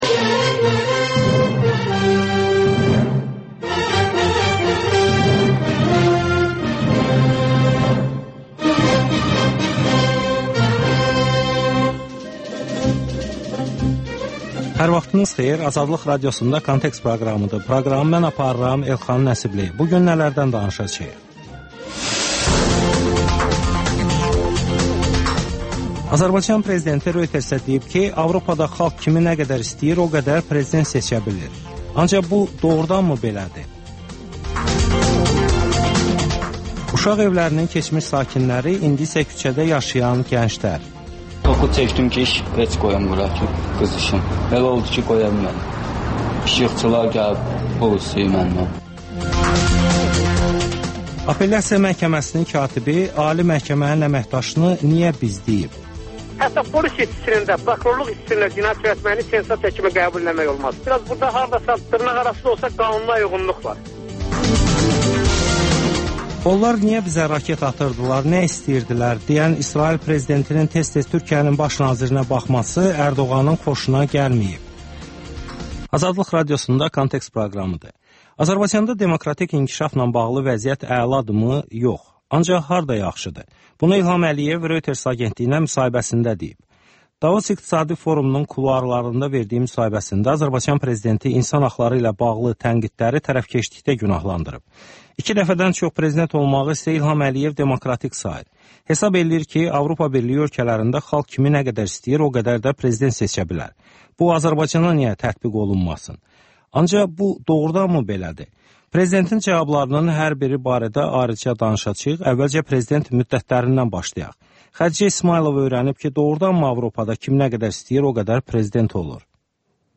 Müsahibələr, hadisələrin müzakirəsi, təhlillər (Təkrar)